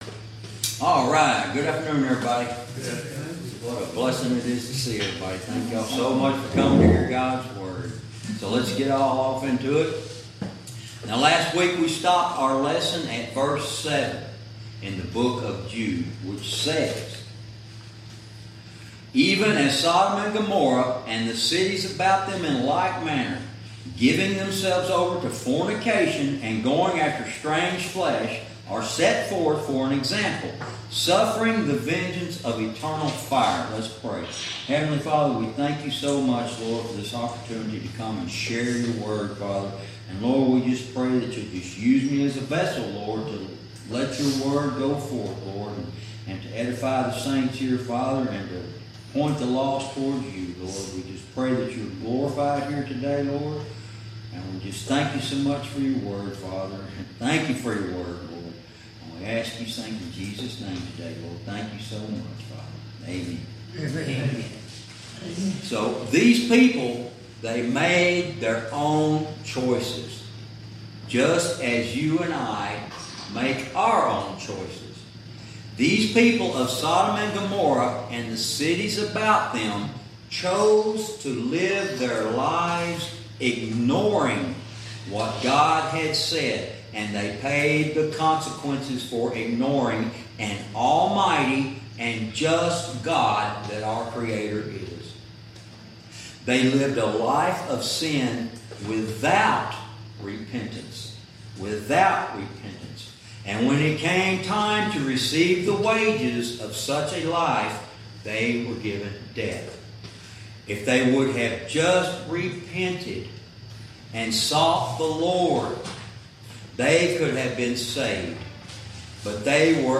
Verse by verse teaching - Lesson 22